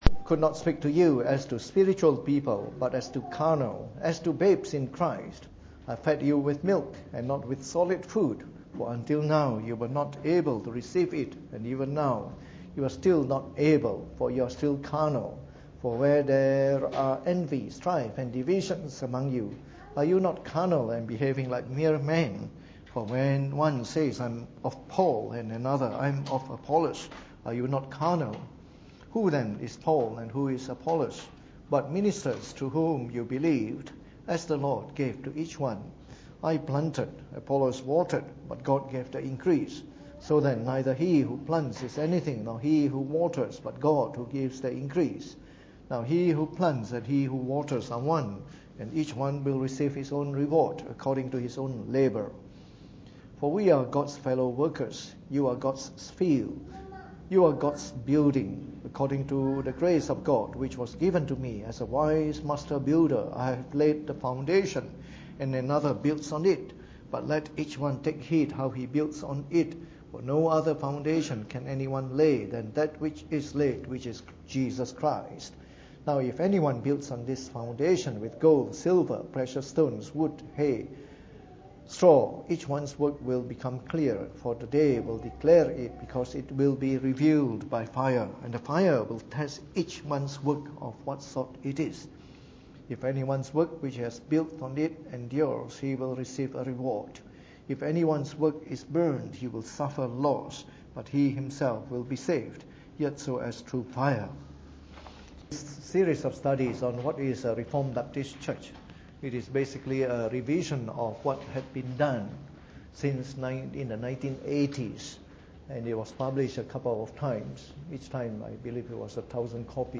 Preached on the 10th of May 2017 during the Bible Study, from our series on Reformed Baptist Churches.